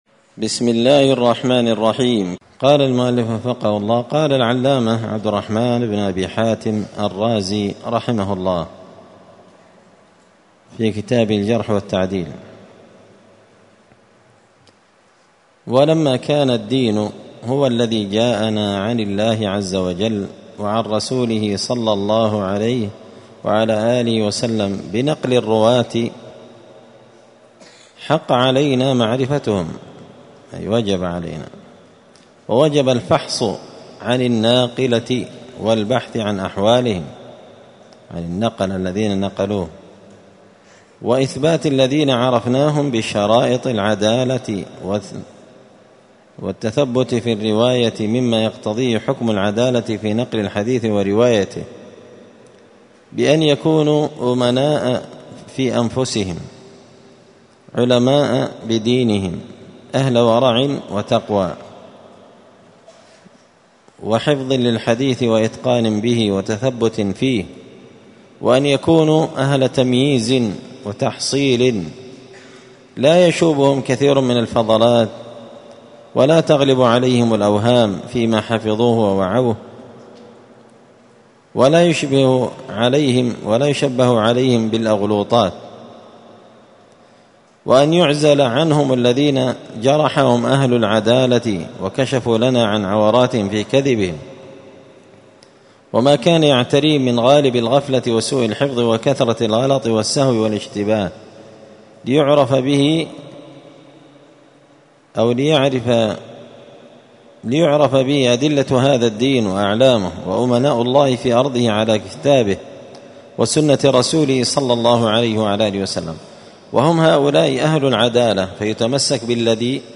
*الدرس الثاني (2) موجب الجرح والتعديل*